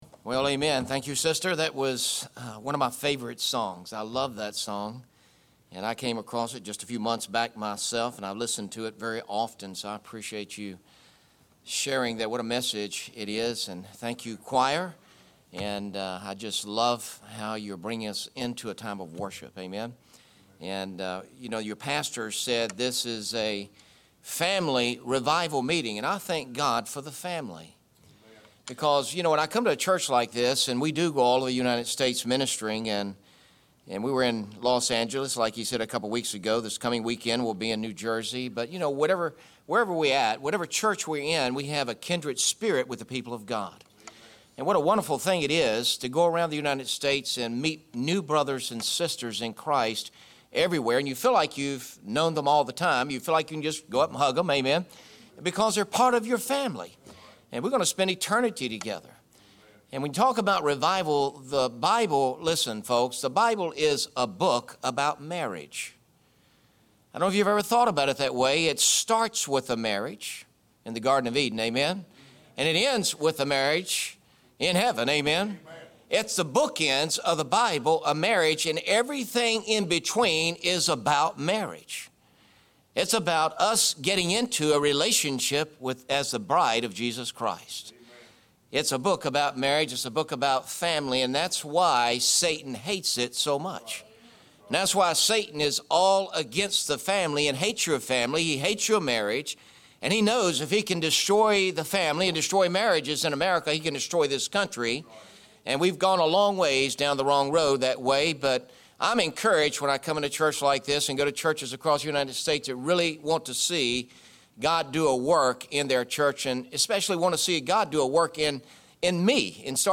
Sermons Archive - Page 9 of 16 - Gourd Springs Baptist Church